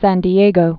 (săn dē-āgō)